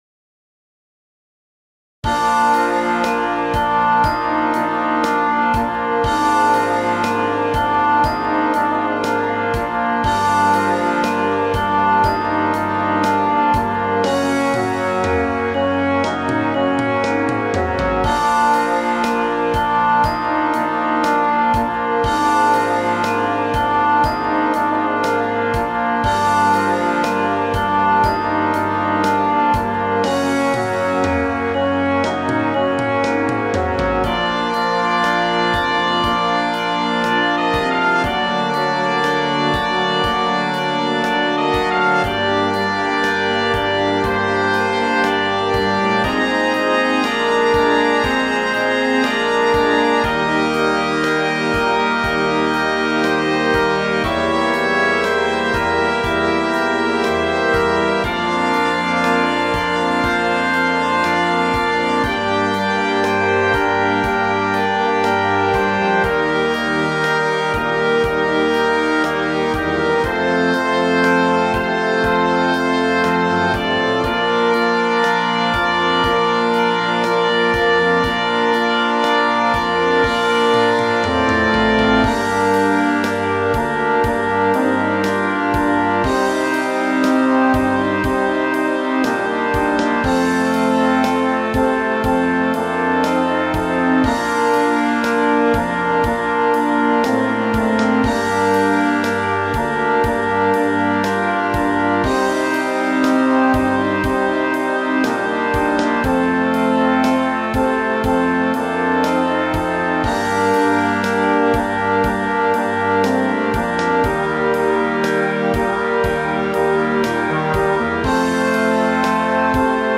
Brass Band
Listen (Computer Generated)